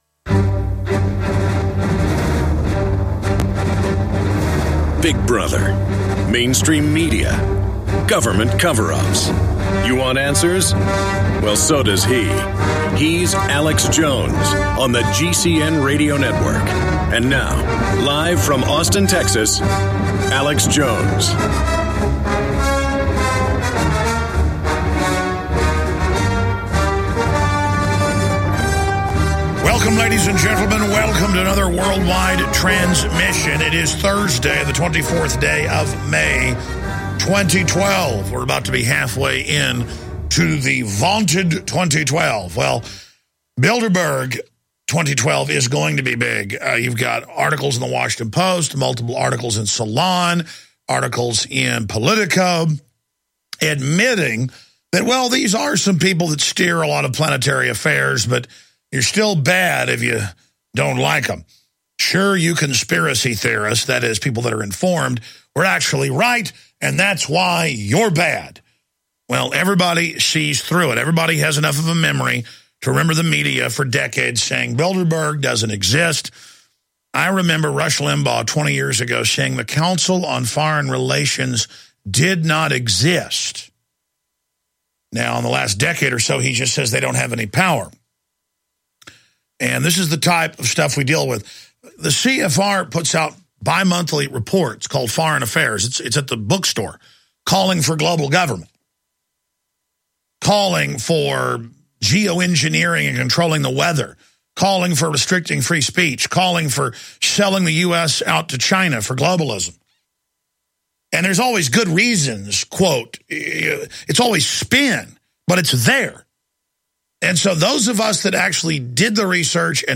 Watch Alex's live TV/Radio broadcast.